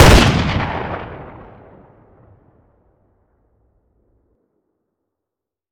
weap_western_fire_plr_atmo_ext1_02.ogg